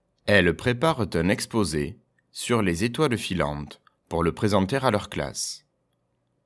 Et enfin, voici les deux phrases de la Twictée 14, lues par le maître pour écrire tout seul !